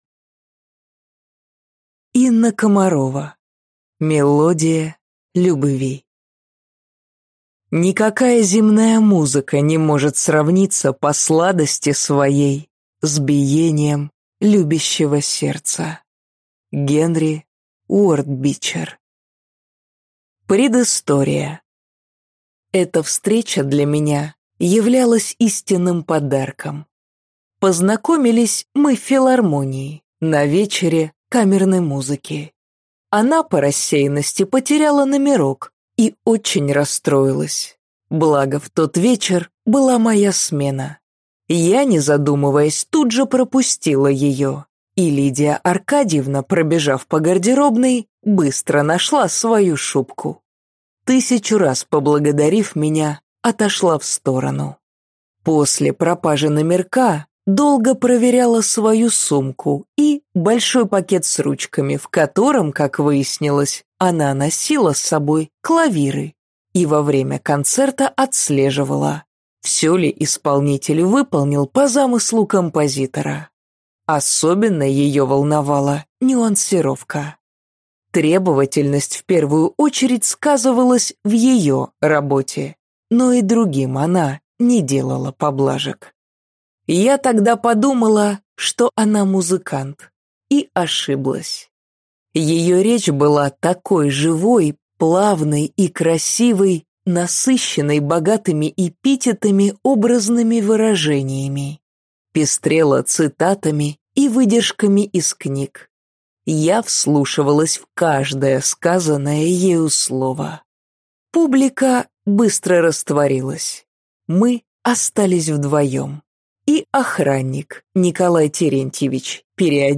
Аудиокнига Мелодия любви | Библиотека аудиокниг